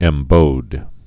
(ĕm-bōd)